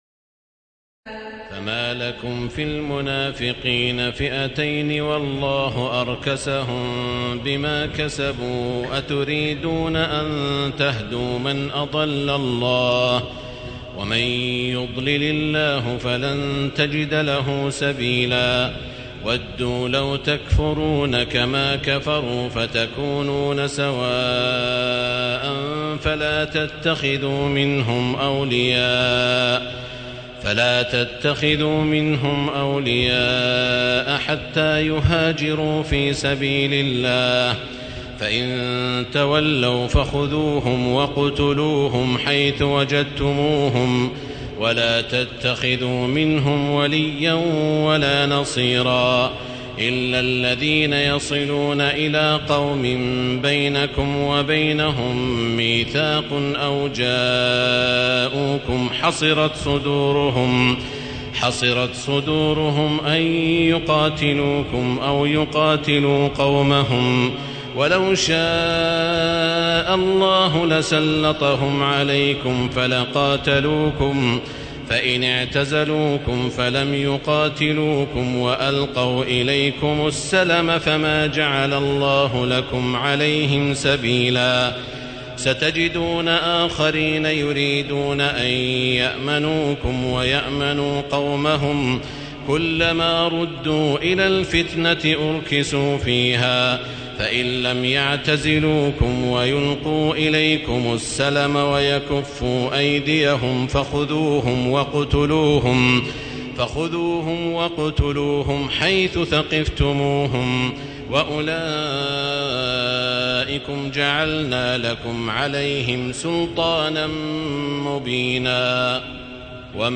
تراويح الليلة الخامسة رمضان 1437هـ من سورة النساء (88-154) Taraweeh 5 st night Ramadan 1437H from Surah An-Nisaa > تراويح الحرم المكي عام 1437 🕋 > التراويح - تلاوات الحرمين